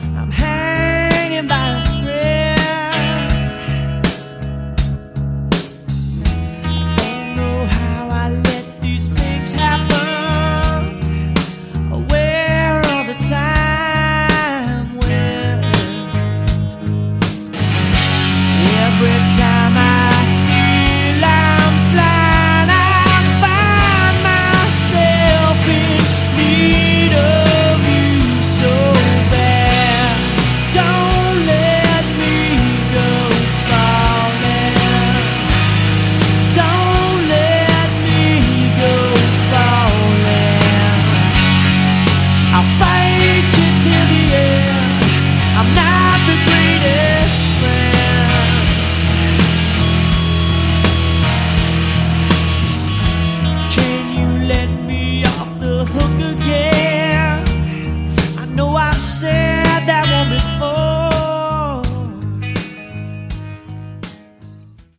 Category: Glammish Hard Rock
lead vocals
drums
bass, vocals
guitar, vocals